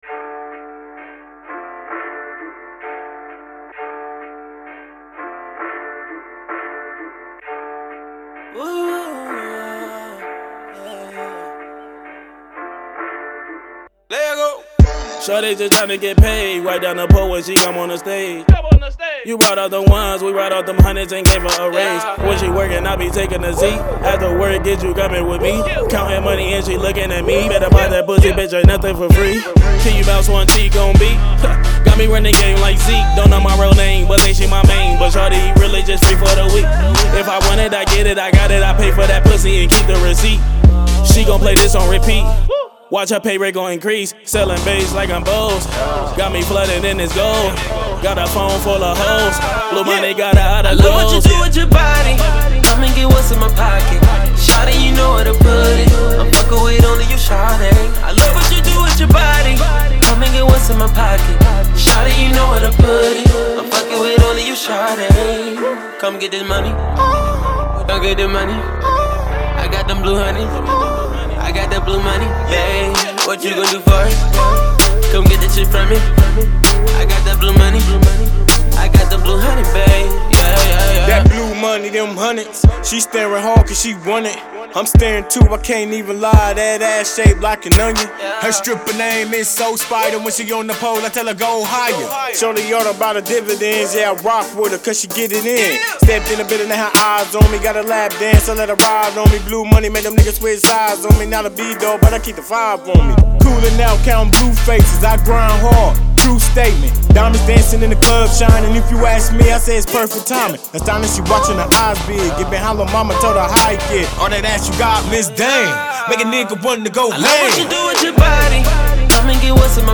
Hiphop
Club Anthem